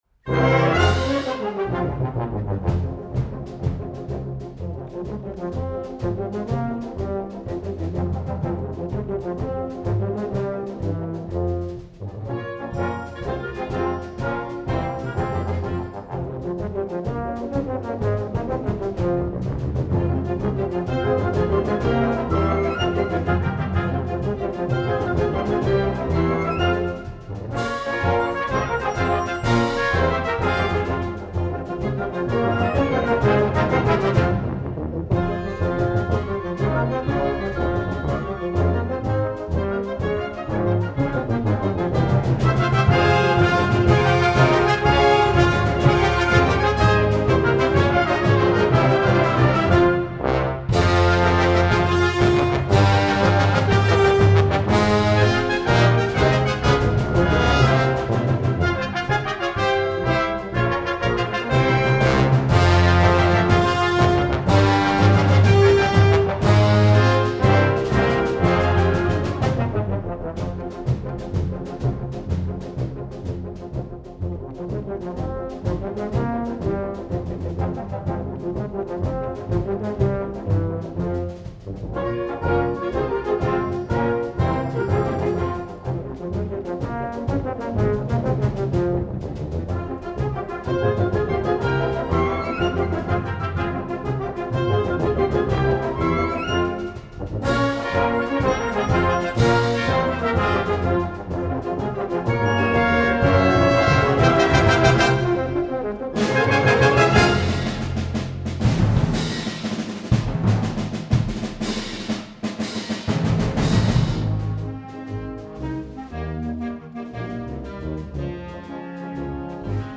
Gattung: Marsch für Blasorchester
Besetzung: Blasorchester